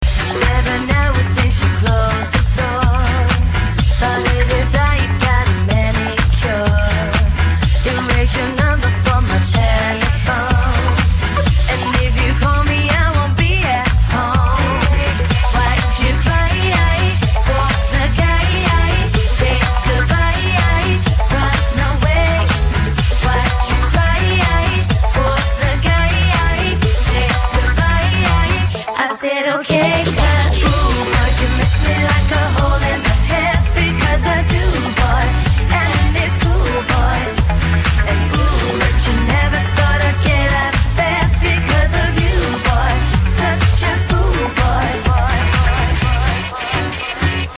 DJ铃声